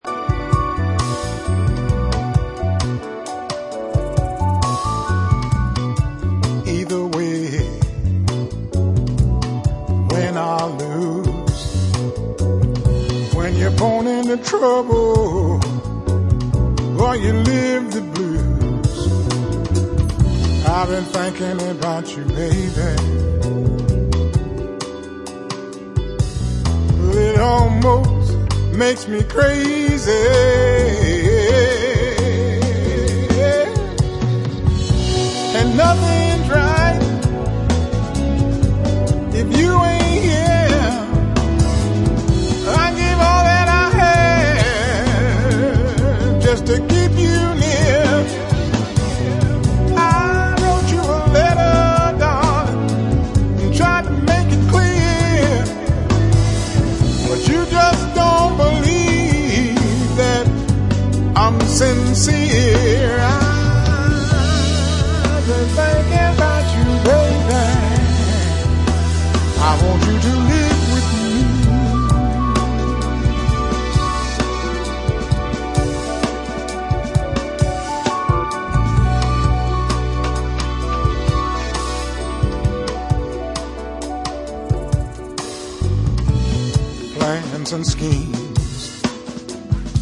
Blues, Funk / soul